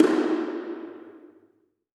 JJPercussion (265).wav